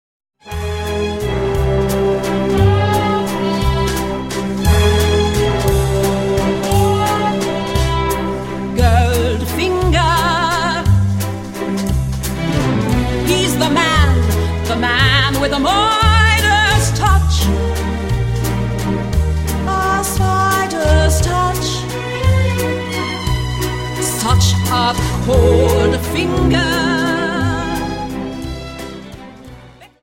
Dance: Viennese Waltz Song